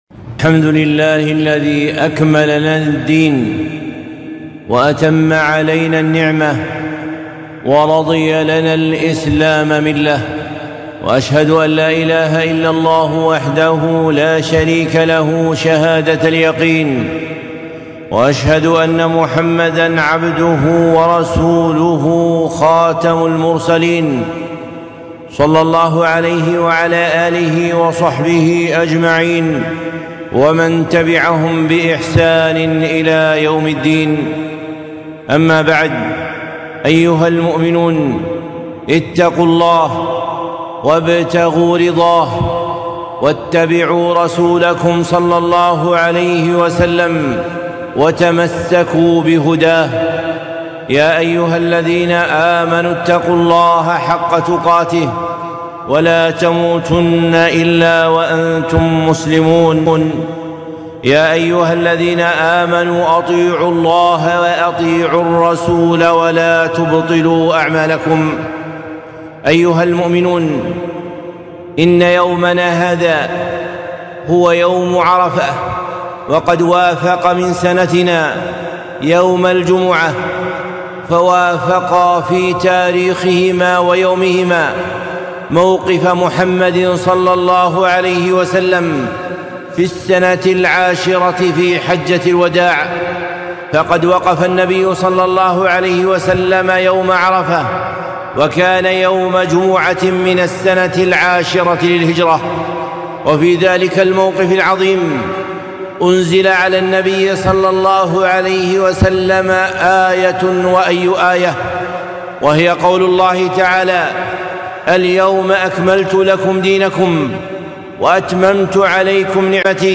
خطبة - تذكروا يوم عرفة يوم الجمعة ٩ ذو الحجة ١٤٤٣